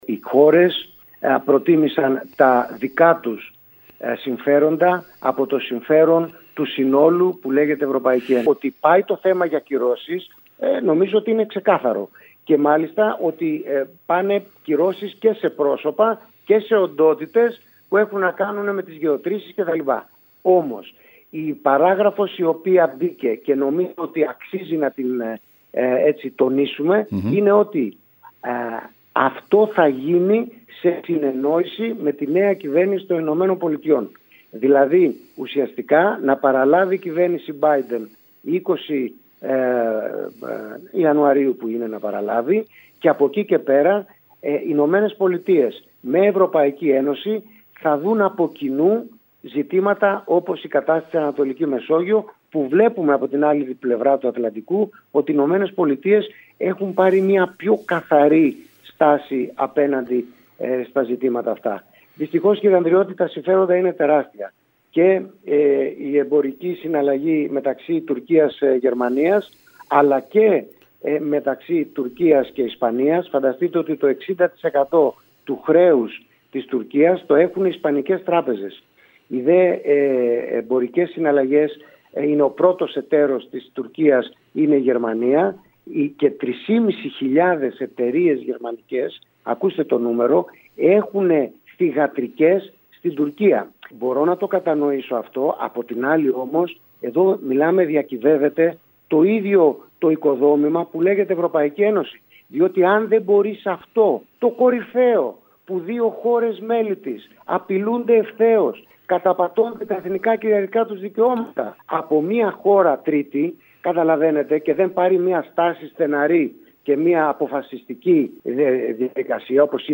Την απόφαση αυτή σχολιάζουν μιλώντας σήμερα στην ΕΡΑ ΚΕΡΚΥΡΑΣ οι βουλευτές Στέφανος Γκίκας και Αλέκος Αυλωνίτης.